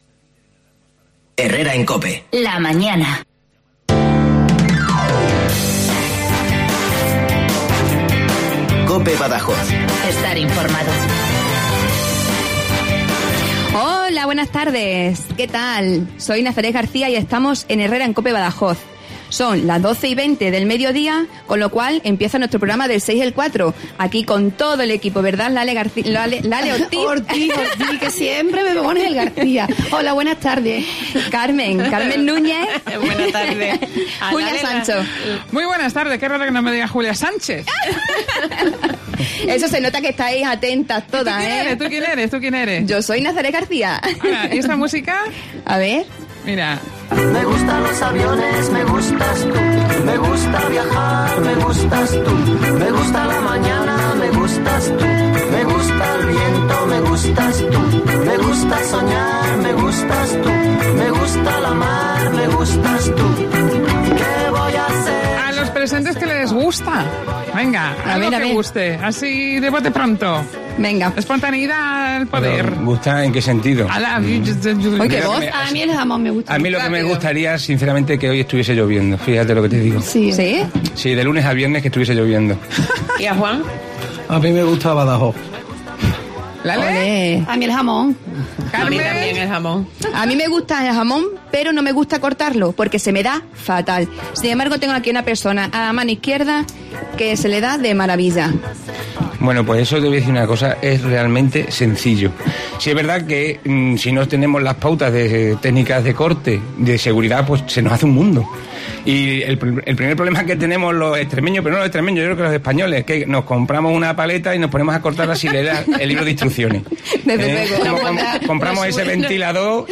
poniendo el toque de humor a la tertulia.